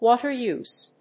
water-use.mp3